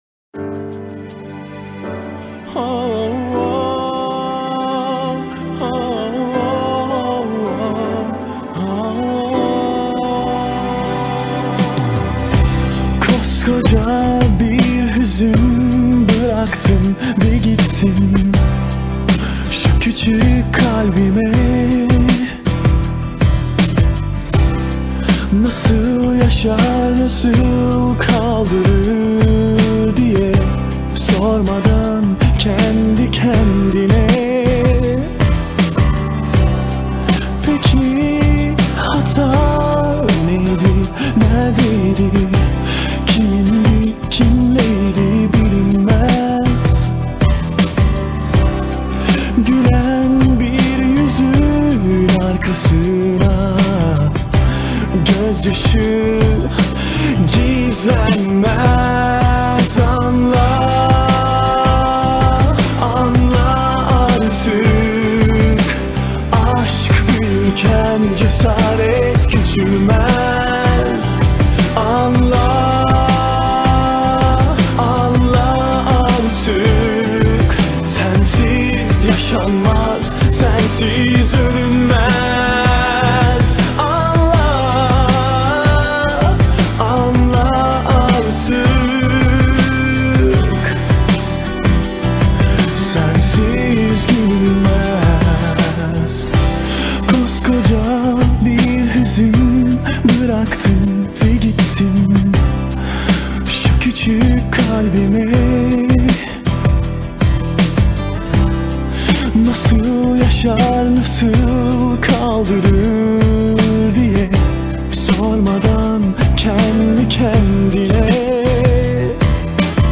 دانلود آهنگ ترکی